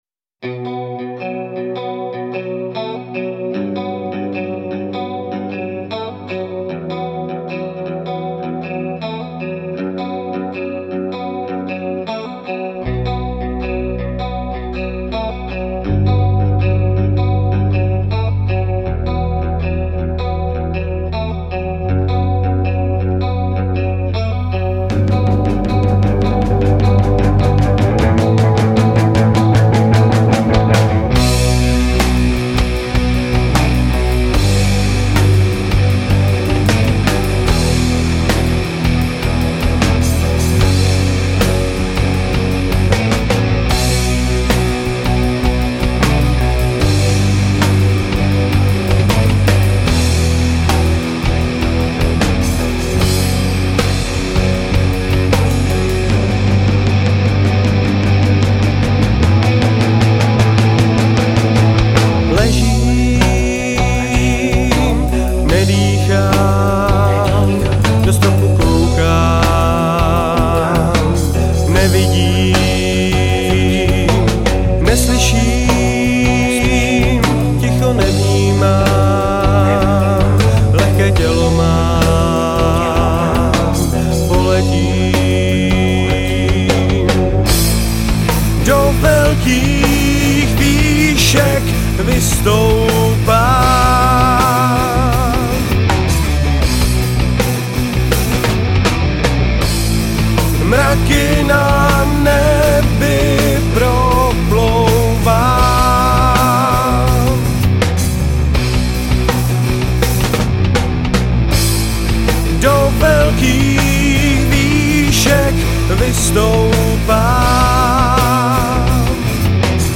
Žánr: Rock
baskytara, zpěv